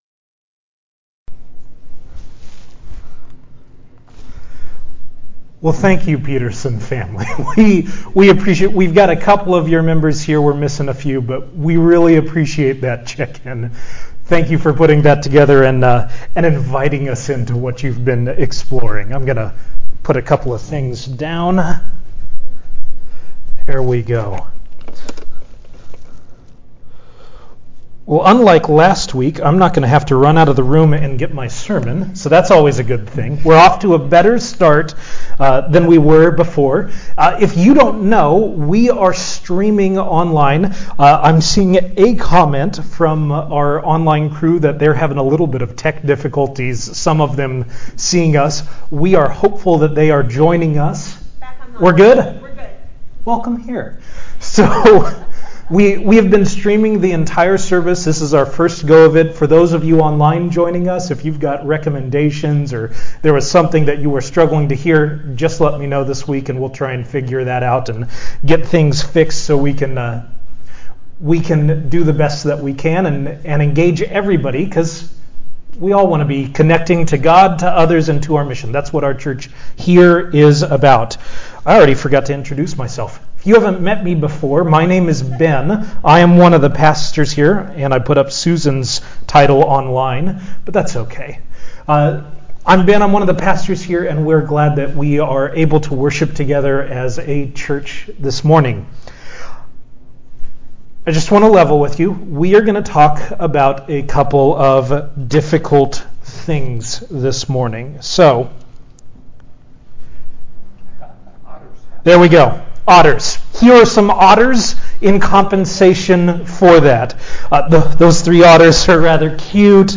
This is the live-stream for our worship service for July 7th, 2020.
Sermon starts at 18:56 SHARE ON Twitter Facebook Buffer LinkedIn Pin It